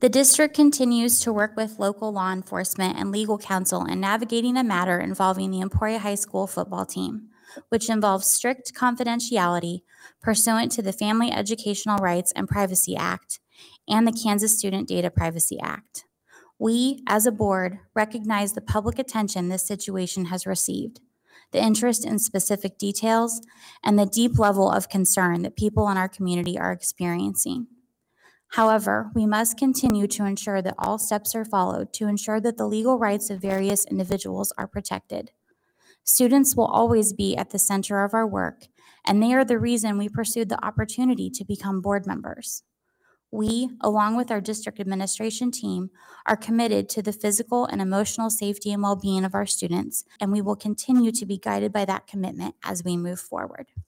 The prepared statement was delivered by Board President Leslie Seeley during the board’s regular meeting.
3366-seeley-statement.wav